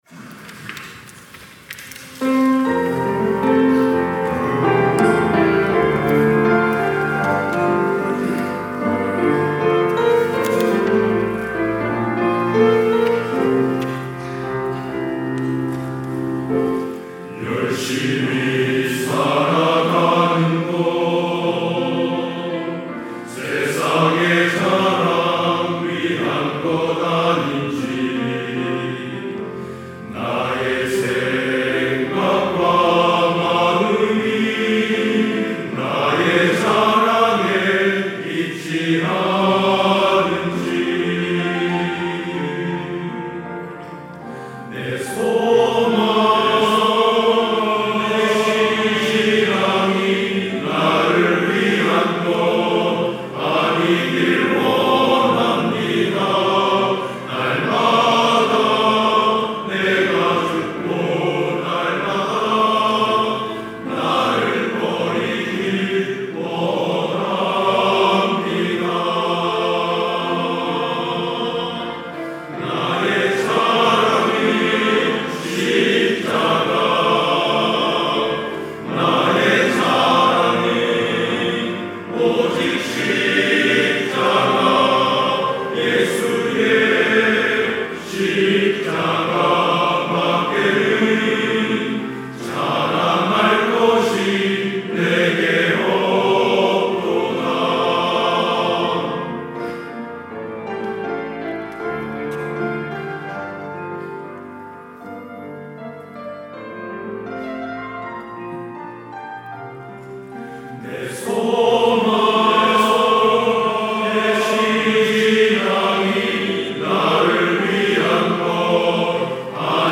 찬양대 남선교회